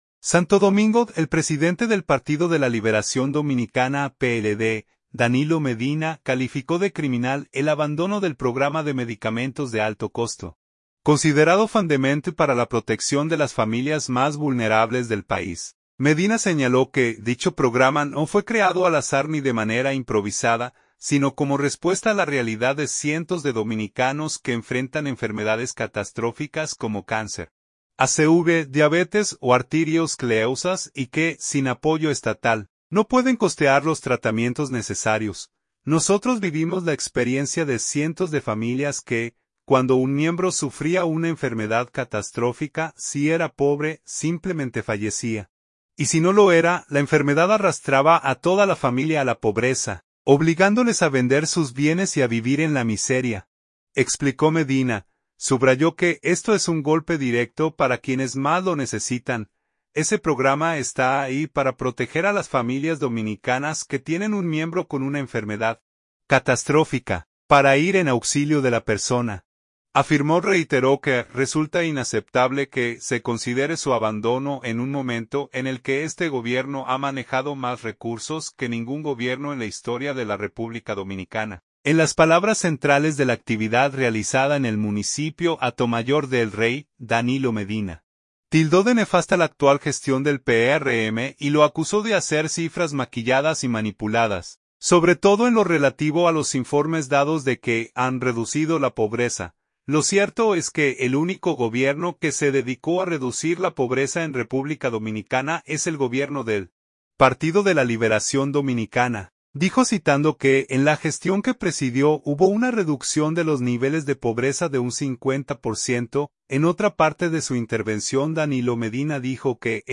En las palabras centrales de la actividad realizada en el municipio Hato Mayor del Rey, Danilo Medina, tildó de nefasta la actual gestión del PRM y lo acusó de ofrecer cifras “maquilladas y manipuladas”, sobre todo en lo relativo a los informes dados de que han reducido la pobreza.